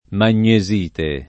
[ man’n’e @& te ]